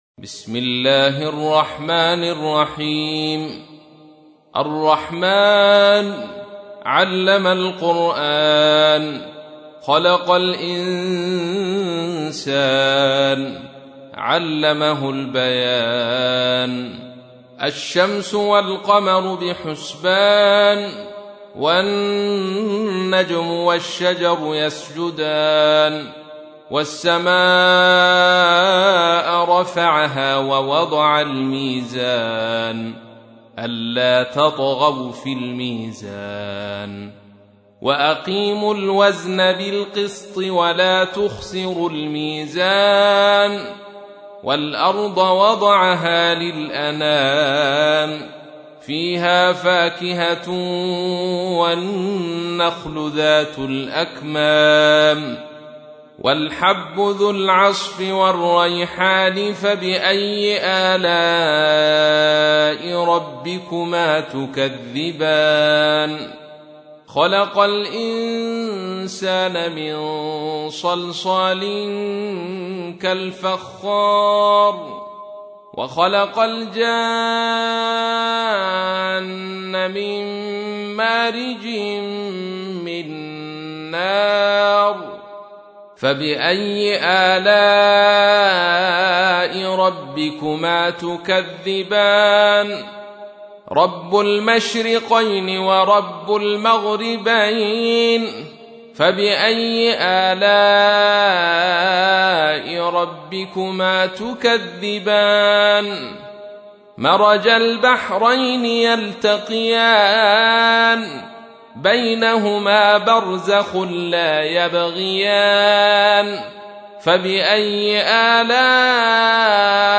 تحميل : 55. سورة الرحمن / القارئ عبد الرشيد صوفي / القرآن الكريم / موقع يا حسين